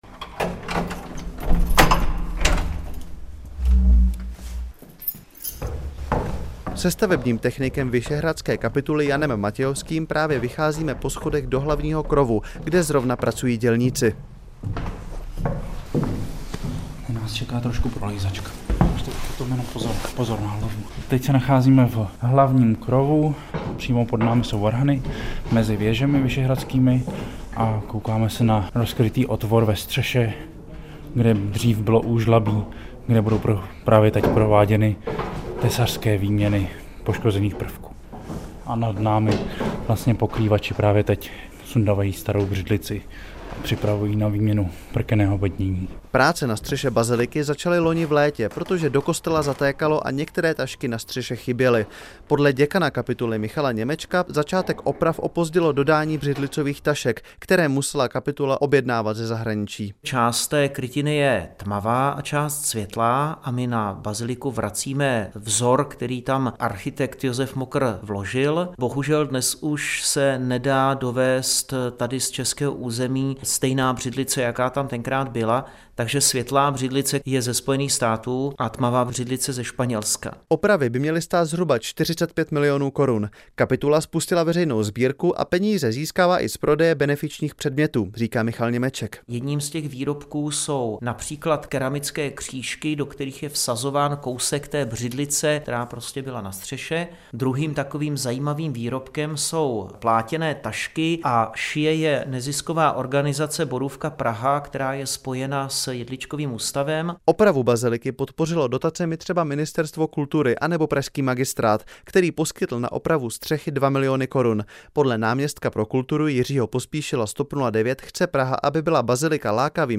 Zprávy Českého rozhlasu Střední Čechy: Na střechu pražské baziliky sv. Petra a Pavla se vrátí původní vzor břidlice.